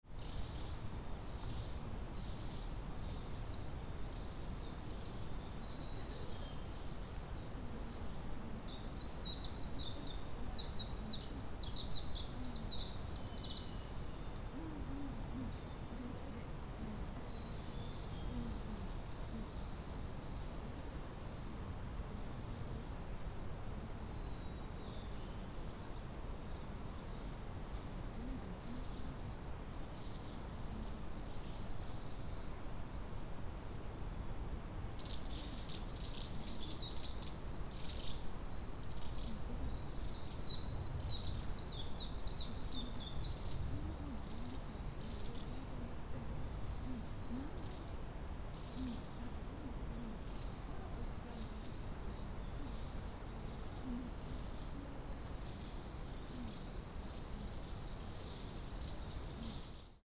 Tit Alley (A walking course where tits frequently sing)
Many birds were singing in the beautiful fresh greenery. Frogs were also singing around the waterside.
When I recorded the soundscape at the tit alley, two women walking at the alley from opposite directions happened to meet about 10 m away from the recording point and started talking.